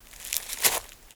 MISC Leaves, Foot Scrape 04.wav